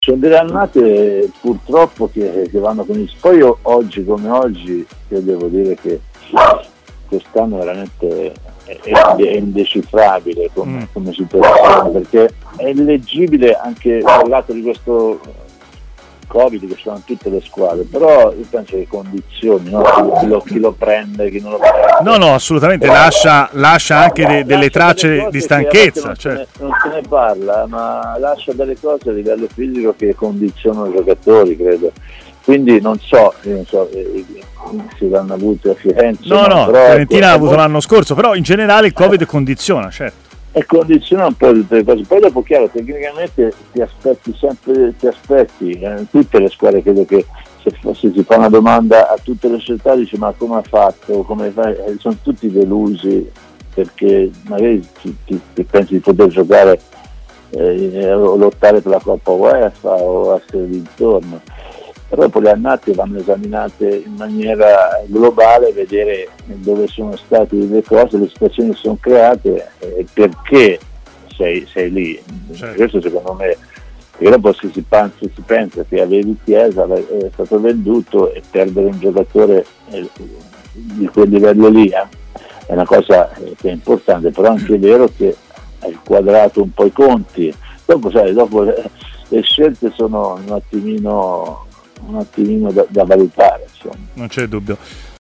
Il tecnico Alessandro Calori ha parlato anche di Fiorentina e corsa salvezza a Stadio Aperto su TMW Radio: "Dai 31 punti in giù, ancora, secondo me di tranquillo non c'è nessuno.